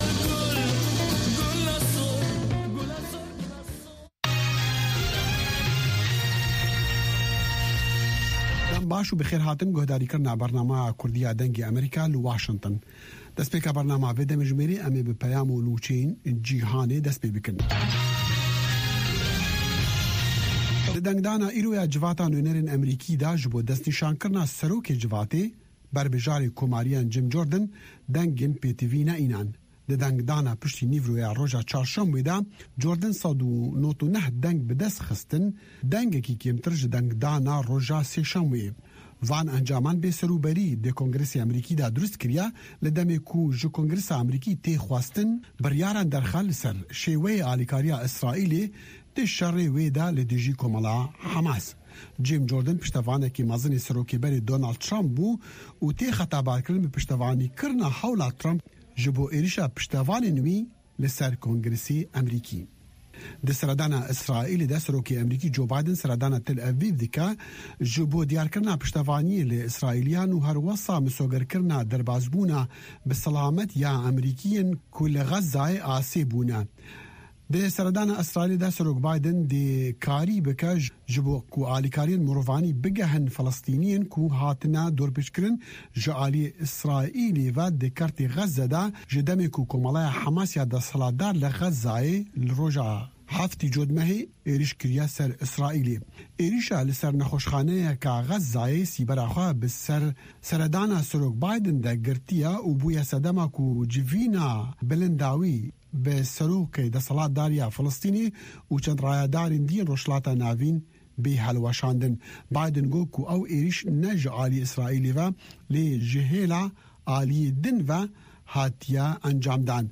هەواڵە جیهانییەکان 2